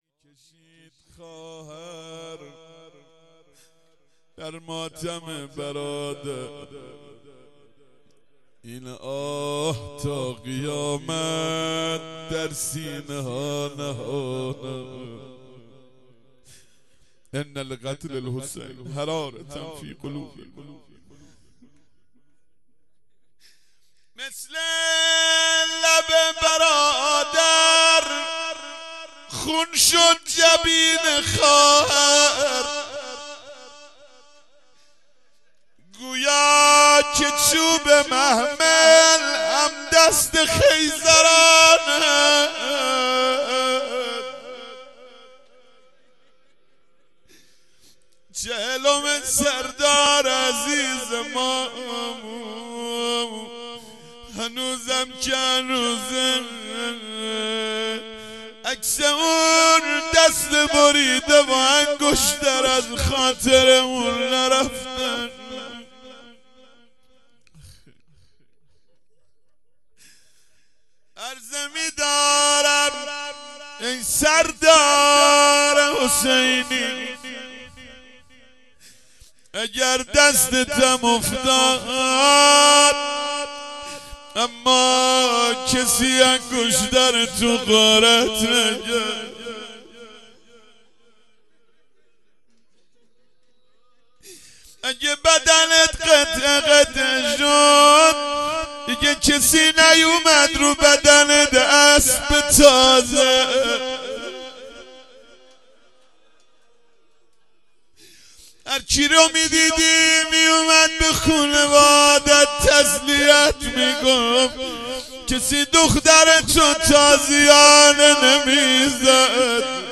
مراسم چهلم سردار قاسم سلیمانی